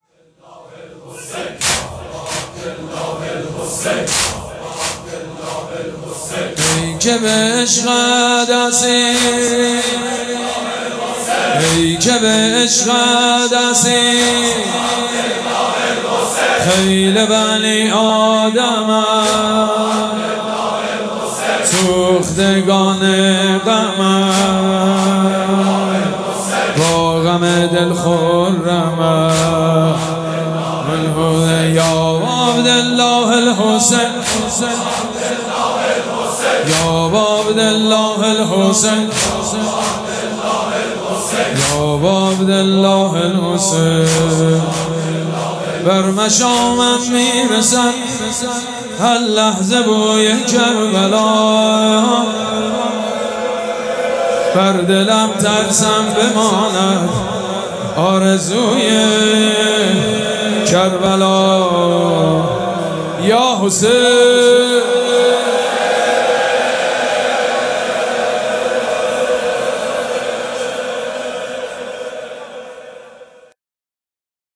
مراسم عزاداری شب دهم محرم الحرام ۱۴۴۷
هیئت ریحانه الحسین سلام الله علیها
حاج سید مجید بنی فاطمه